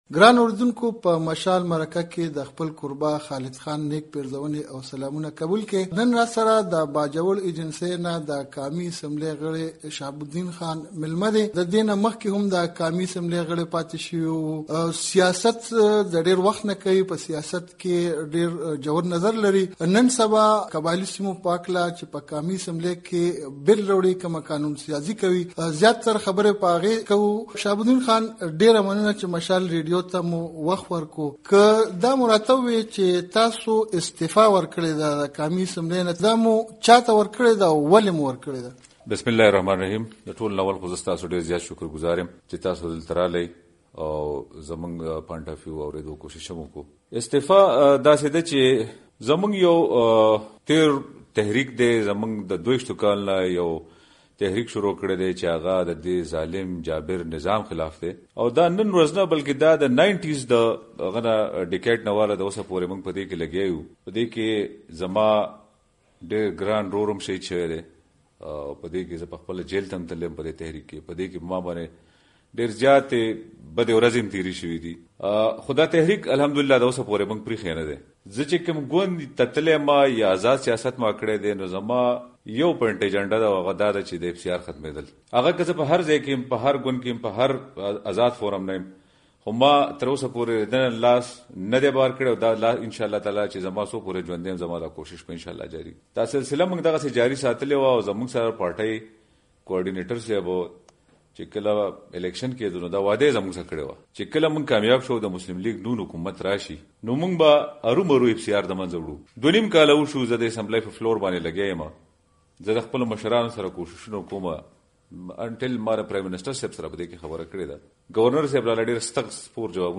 شهاب الدین خان سره مرکه دلته واورئ